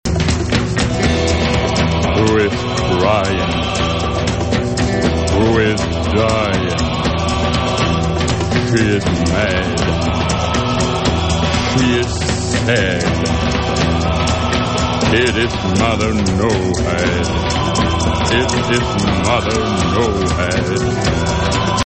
Beide zwart/wit en van nederlandse makelij.